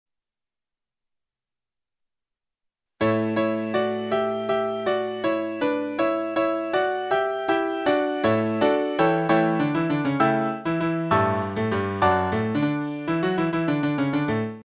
楽譜作成ソフトでできる音で、はなはだ非音楽的だが、テンポは忠実に再現するので聞いてもらいたい。
そしてEx.2は原曲のテンポ指定どおり弾いた場合である。テンポの変化は機械なので無感情である。